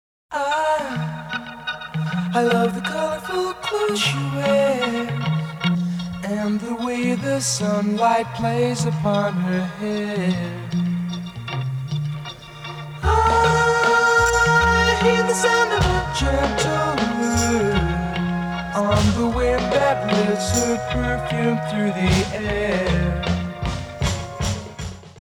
мужской вокал
психоделический рок
surf rock
Старый рок, конца 60-х